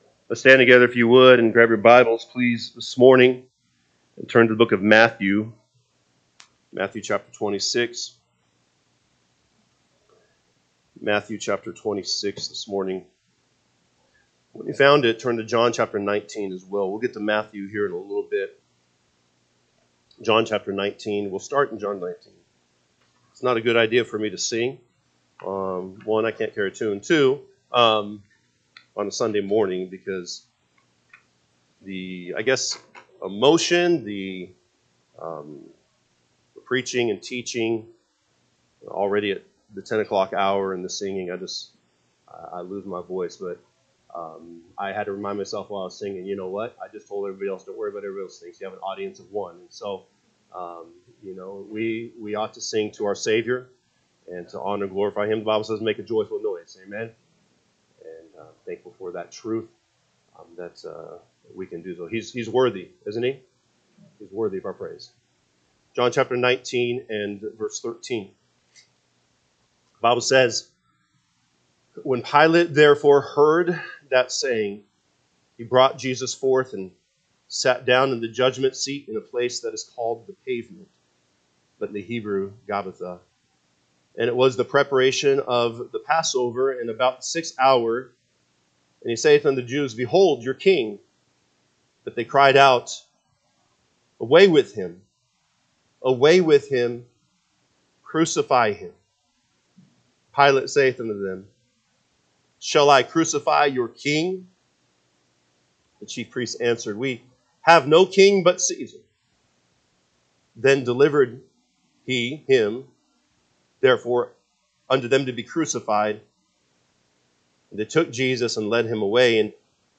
April 20, 2025 am Service John 19:13-30 (KJB) 13 When Pilate therefore heard that saying, he brought Jesus forth, and sat down in the judgment seat in a place that is called the Pavement, but …